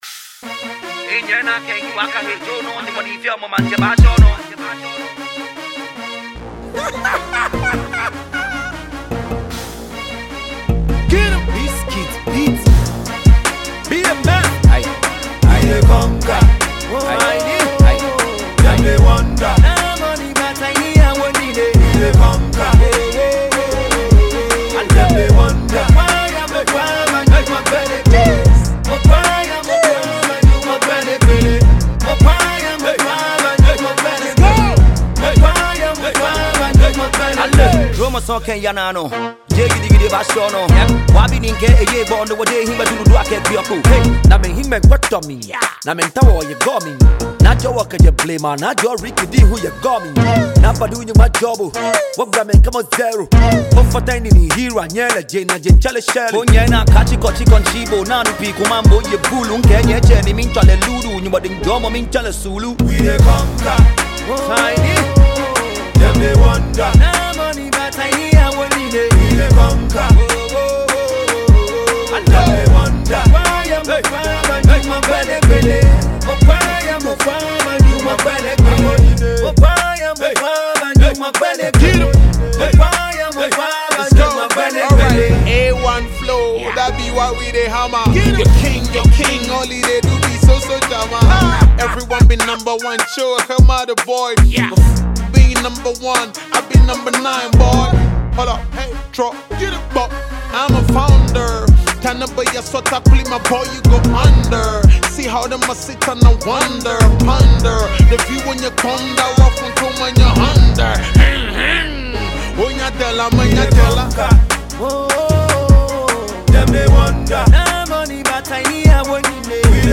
masterful Ga rap delivery and energetic style